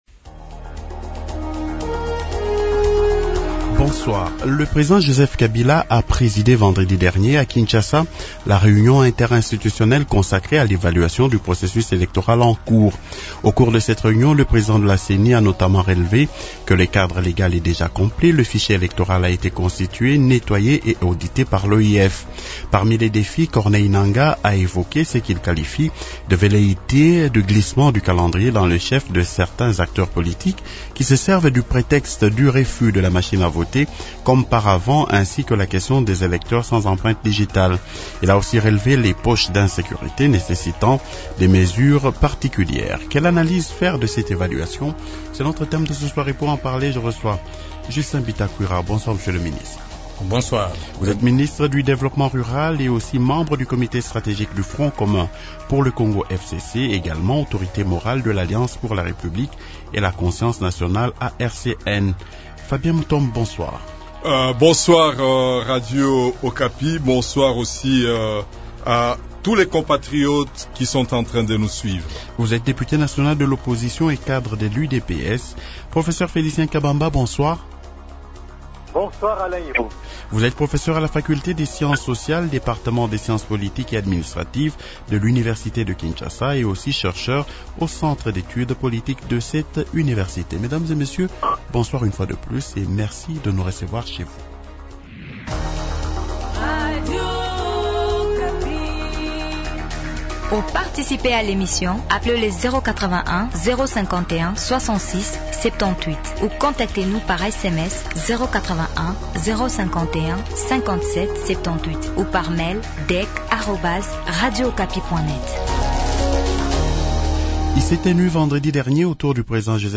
-Quelle analyse faire de cette évaluation ? Invités : -Justin Bitakwira, Ministre du développement rural.
-Fabien Mutomb, Député national de l’opposition et cadre de l’UDPS.